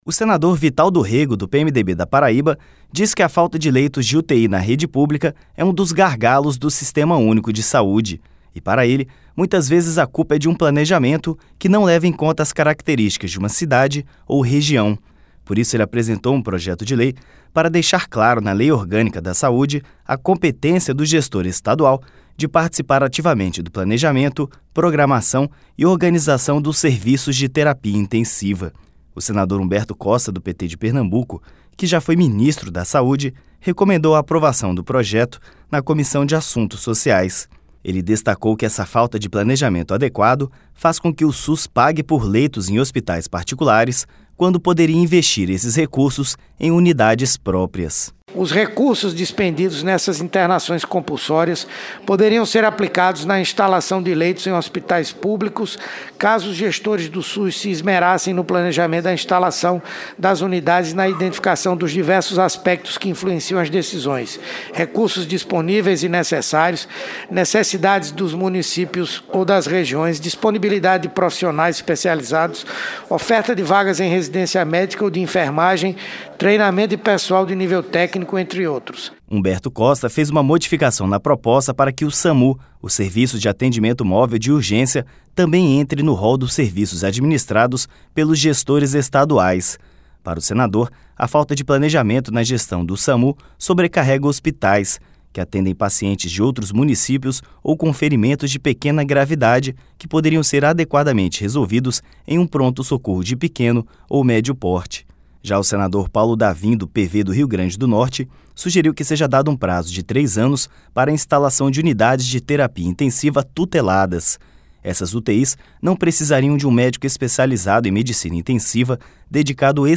O senador Humberto Costa, do PT de Pernambuco, que já foi ministro da Saúde, recomendou a aprovação do projeto na Comissão de Assuntos Sociais.